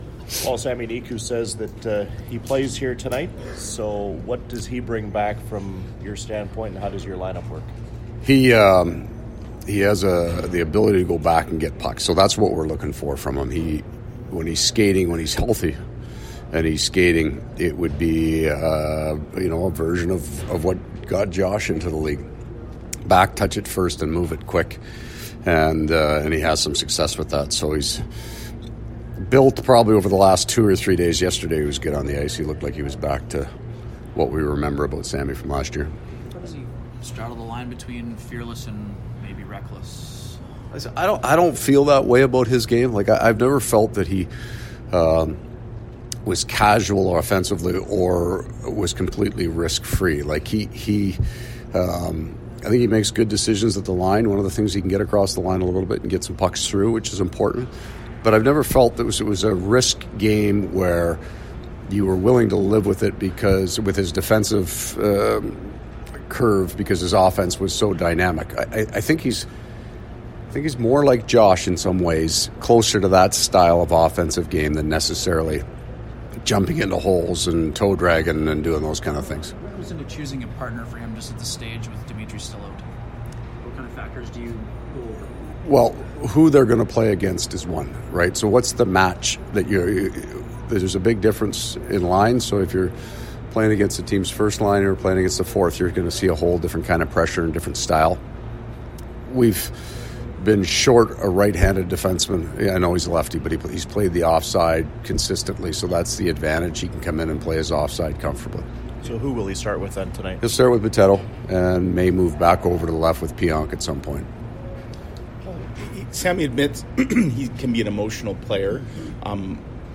A look at the game 43 projected lineups and pre-game audio.
January-6-2020-Coach-Maurice-pre-game.mp3